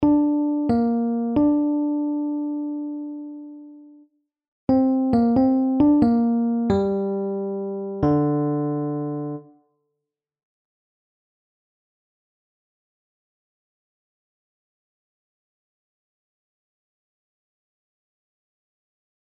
Grote terts